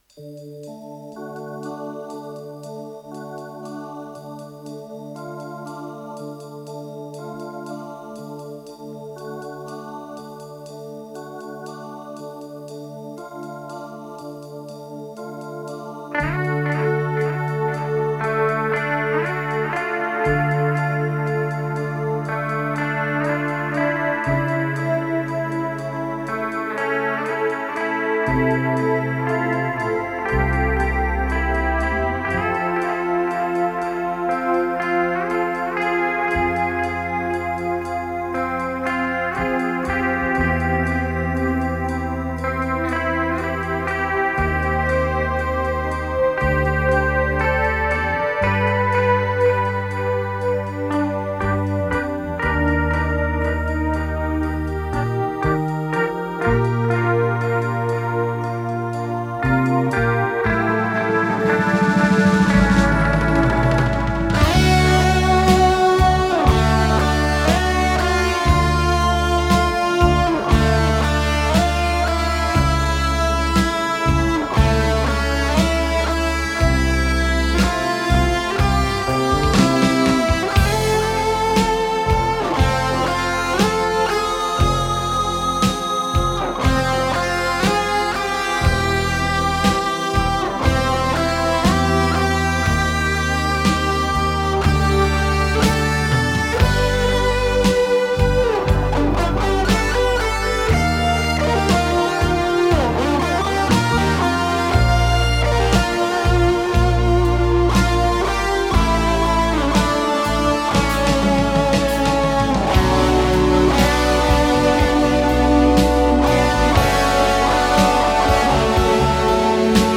ПодзаголовокИнструментальная пьеса
ВариантДубль моно